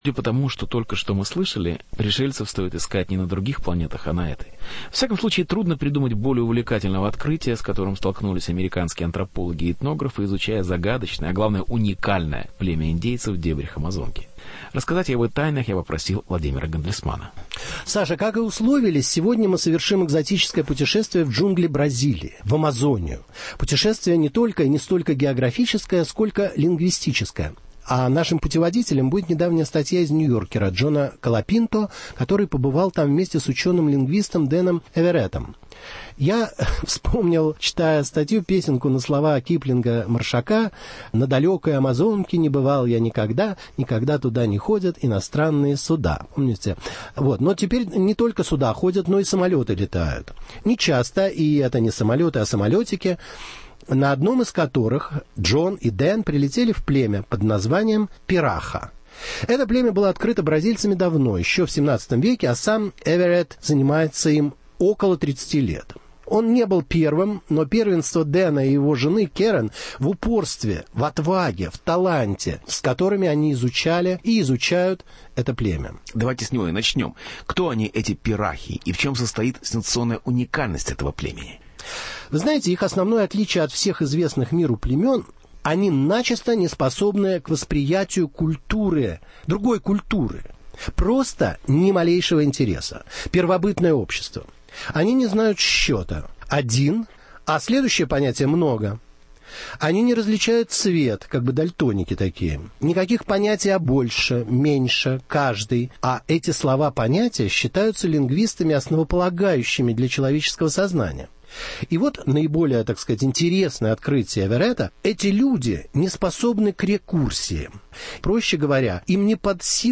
Люди настоящего времени: тайны уникального бразильского племени пираха. Беседа с Владимиром Гандельсманом